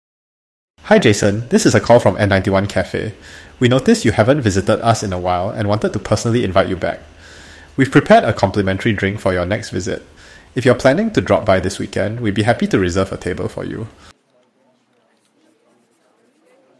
Singapore English Voice Agent
ai-customer-winback-call-singapore.mp3